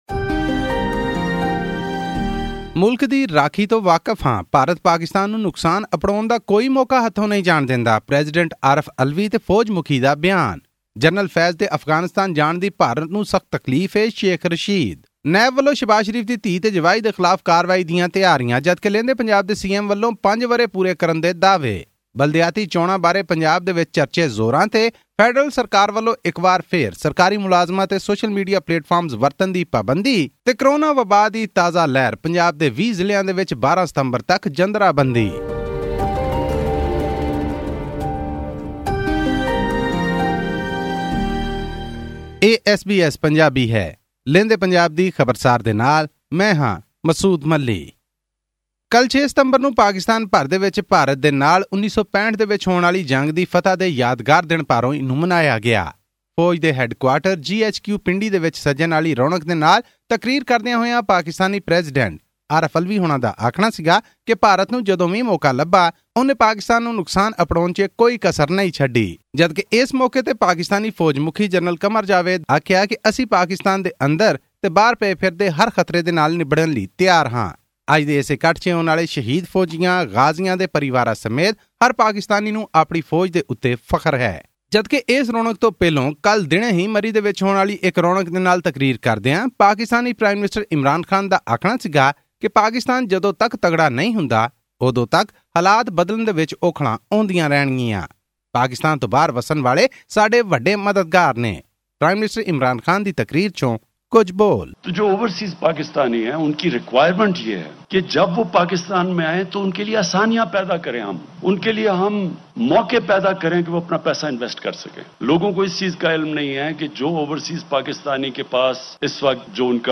pak_punjab_report_for_7th_september_2021.mp3